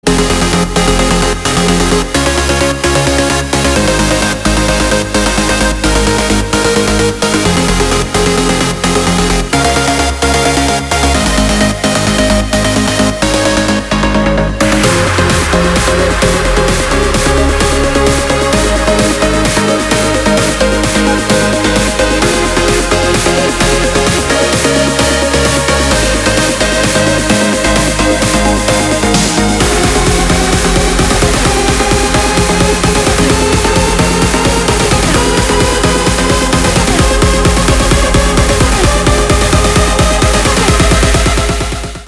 • Качество: 320, Stereo
красивые
электронная музыка
без слов
Trance